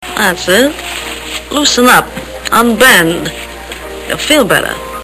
Tags: Mae West Mae West movie clips Come up and see me some time Mae West sound Movie star